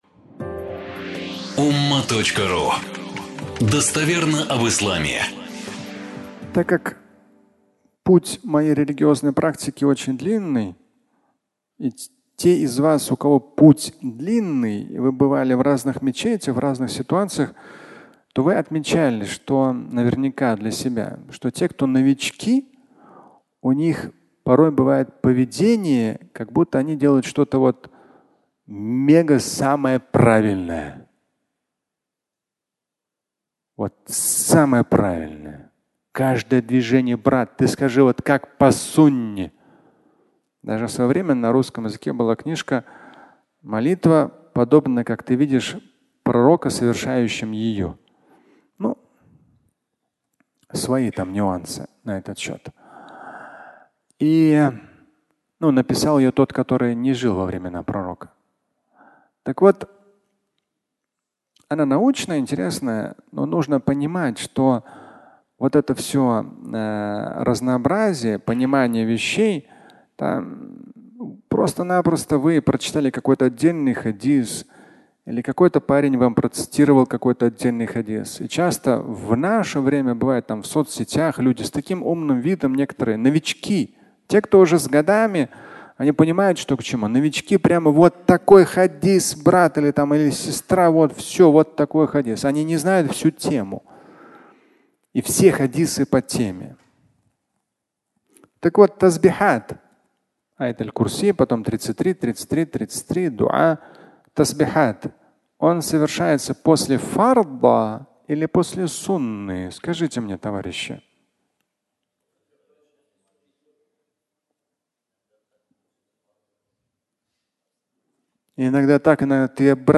Когда совершать тасбихат? (аудиолекция)
Фрагмент пятничной лекции
Пятничная проповедь